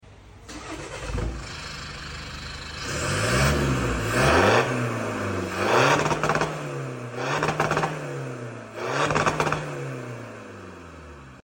Seat leon 1.6 tdi dizel sound effects free download
Seat leon 1.6 tdi dizel egzoz sesi ✅ ➡ Downpipe ➡ 60 mm 304 krom